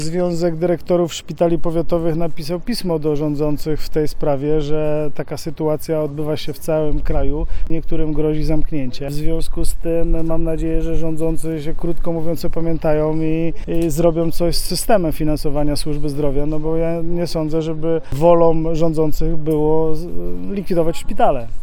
Burmistrz Robert Czapla uważa, że bez potrzebnych zmian w budżecie państwa będzie tylko gorzej.